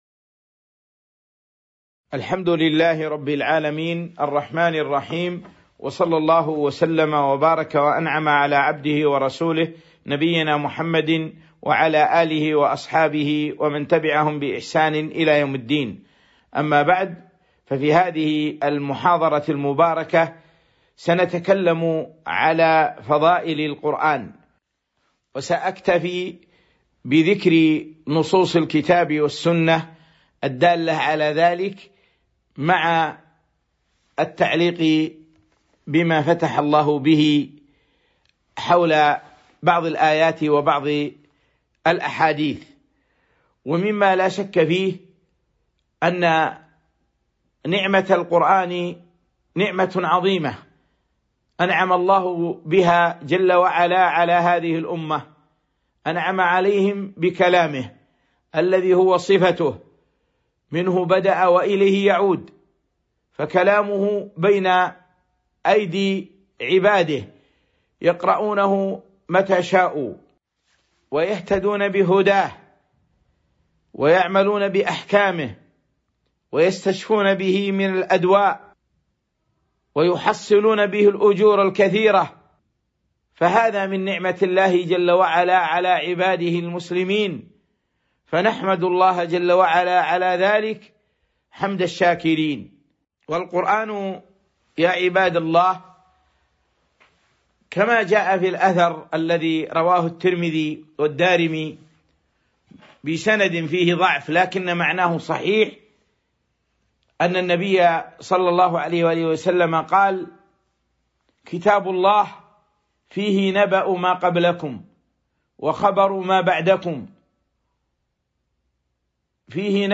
تاريخ النشر ١١ جمادى الآخرة ١٤٤٣ هـ المكان: المسجد النبوي الشيخ